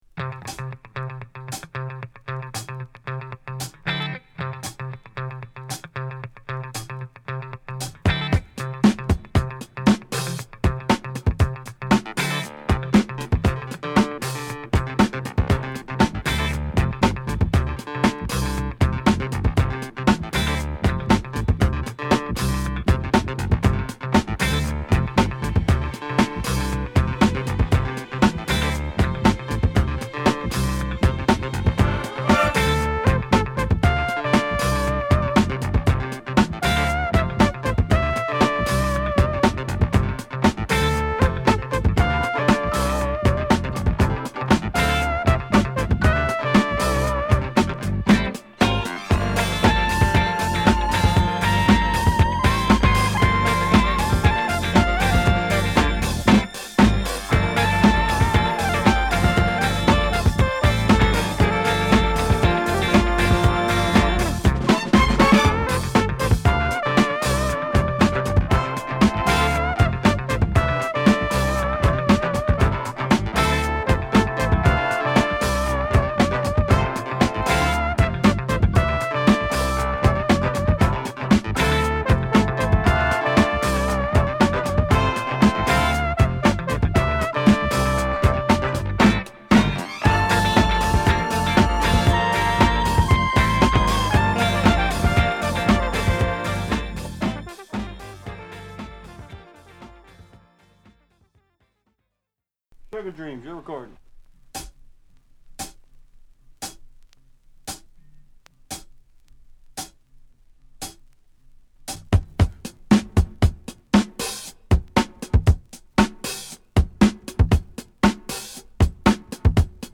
カッティングギターに絡むファットなドラム